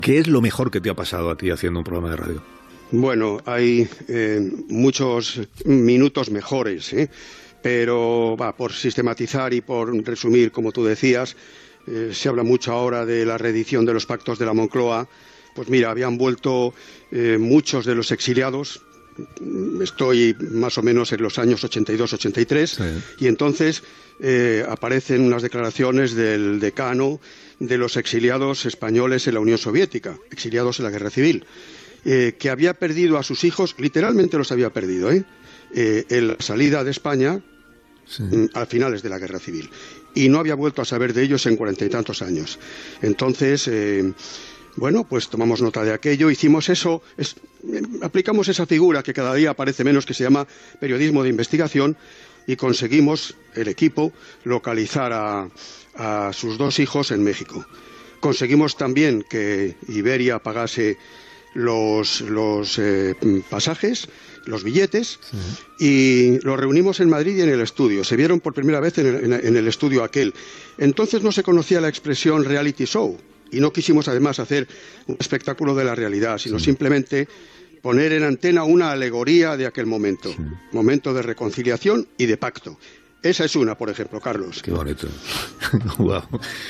Homenatge a la ràdio en temps de Covid. Fragments d'entrevistes a:
Info-entreteniment